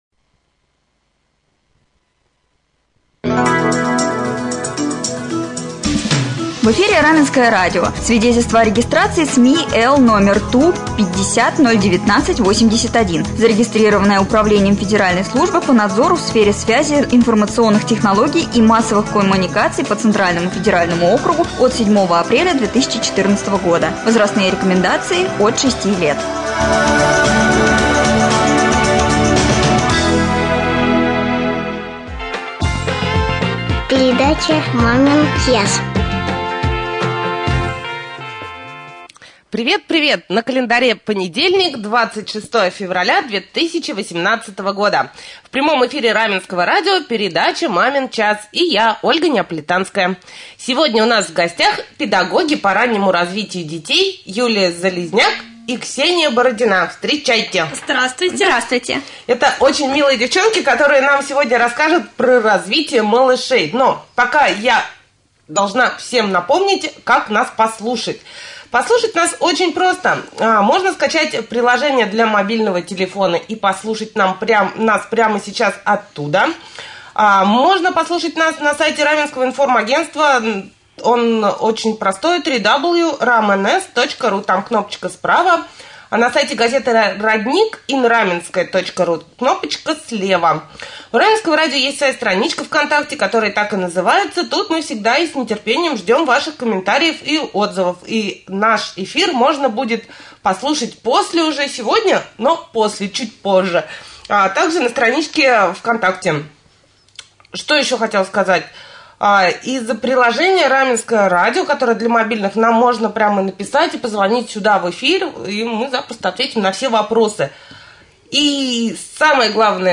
Гости студии — педагоги по раннему развитию детей